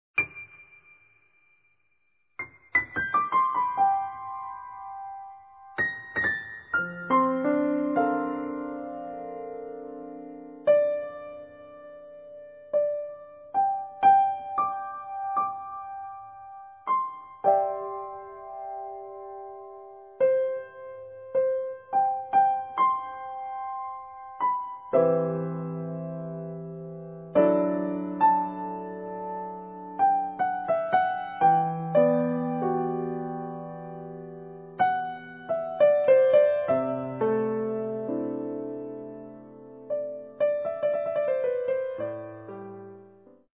An emotional collection of piano solos